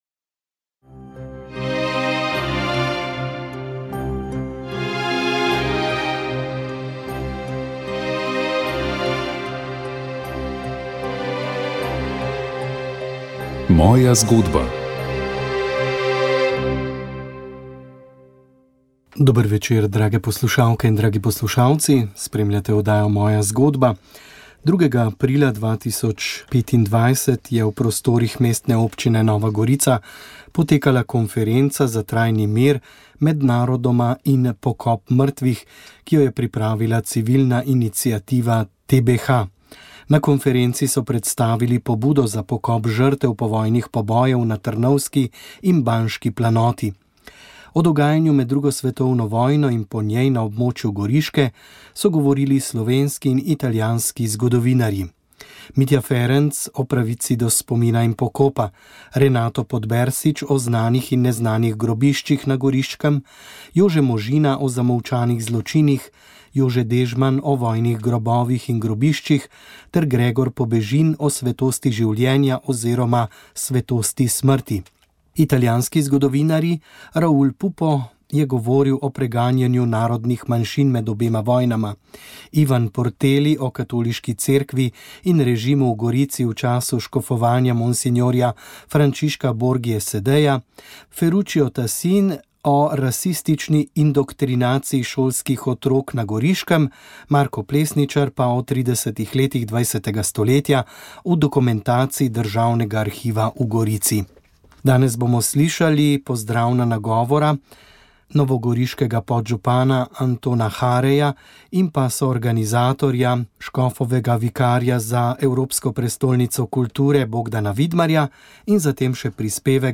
Cerkev na avstrijskem Koroškem je od nekdaj bila gonilna sila tudi za slovensko kulturno udejstvovanje, ki je dvigovalo in narodnostno oblikovalo narod. V današnjem svetu pa sta tako vera in slovenska beseda pred novimi izzivi. Tako razmišlja škof krške škofije v Celovcu dr. Jože Marketz. Svoje razmišljanje je pripravil za letošnje Koroške kulturne dneve v Ljubljani, za večer, ki ga je organiziral Klub koroških Slovencev v Ljubljani.